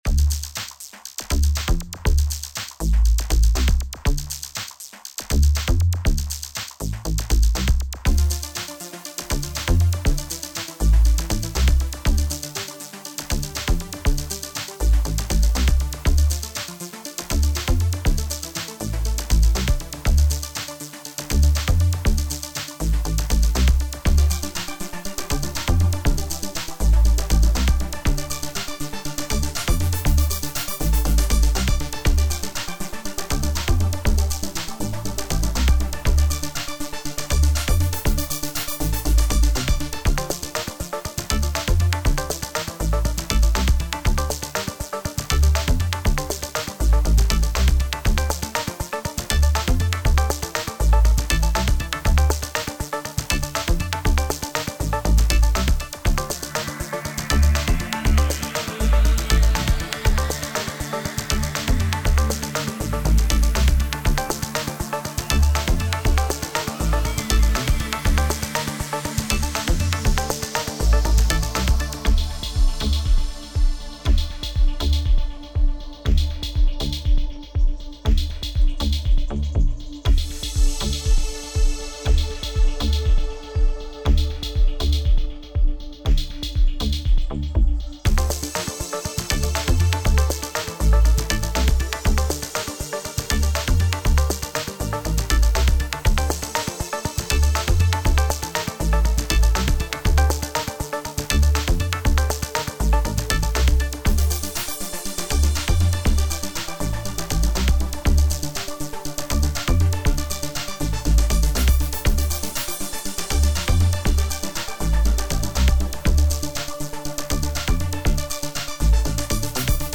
Home > Music > Electronic > Running > Chasing > Restless